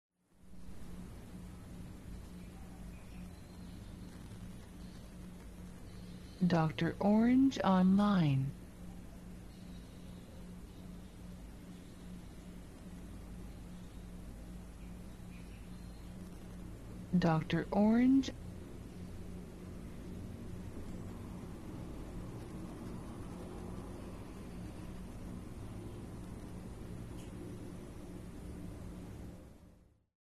Ambiente Estéreo Interior Cocina Perro a lo Lejos
INTERIOR COCINA, AMBIENTE ESPACIO, PERRO LADRA LEJOS, SONIDO CONTINUO (2min01seg).
Archivo de audio ESTÉREO, 96Khz – 24 Bits, WAV.
INTERIOR-COCINA_AMBIENTE-ESPACIO_PERRO-LADRA-LEJOS-96KHZ.mp3